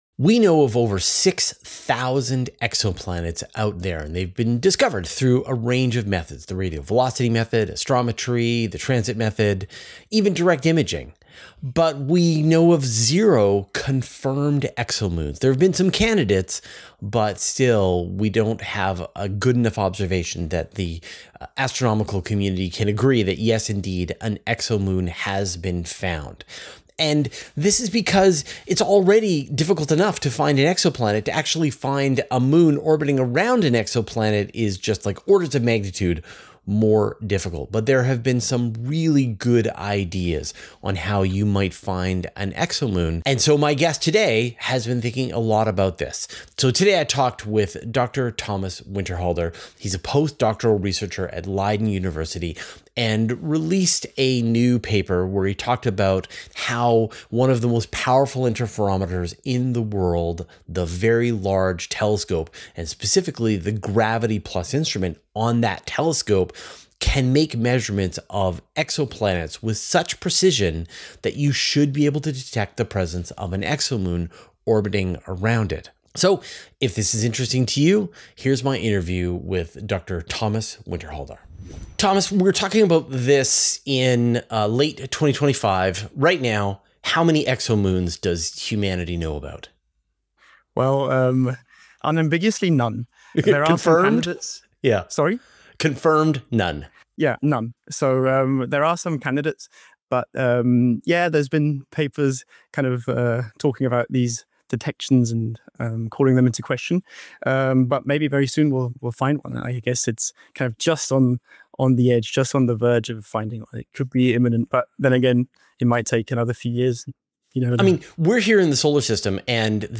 But how can we find them and what can we expect from the future discoveries? Finding out in this interview.